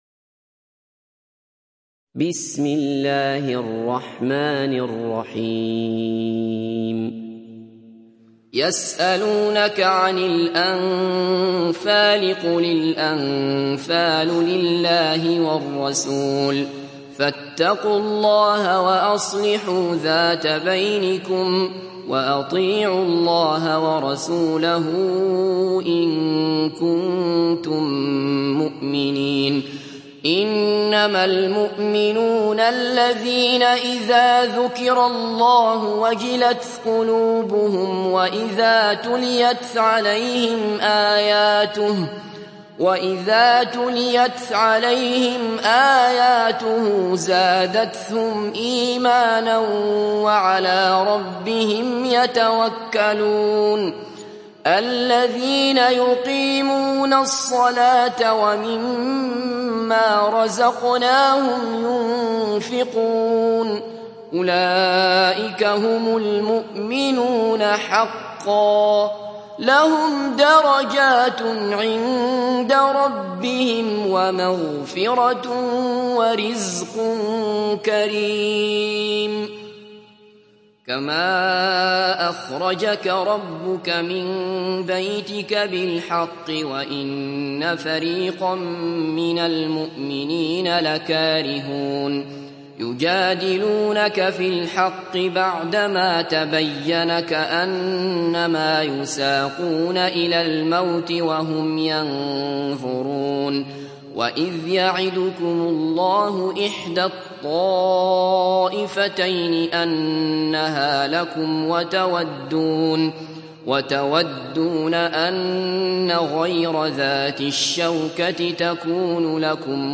Surah Al Anfal Download mp3 Abdullah Basfar Riwayat Hafs from Asim, Download Quran and listen mp3 full direct links